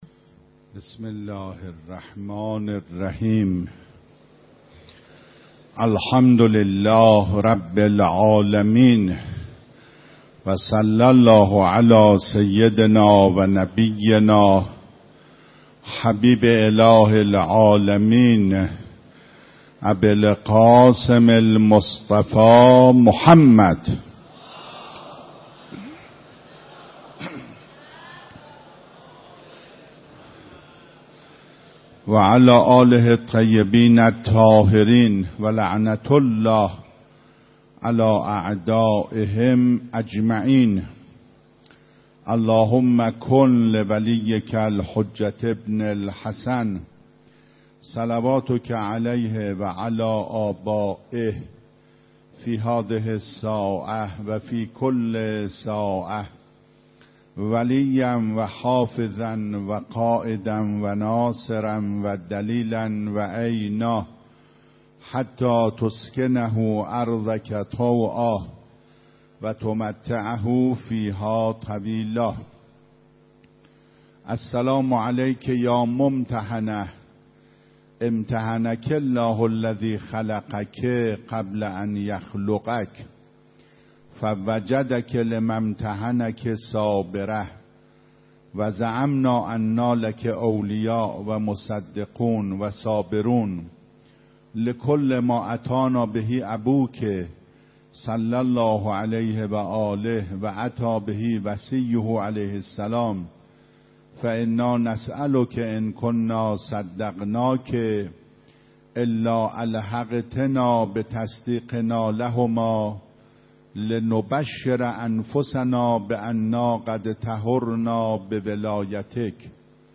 26 بهمن 96 - حرم حضرت معصومه - شرح زیارت حضرت زهرا علیهاالسلام
فاطمیه 96 سخنرانی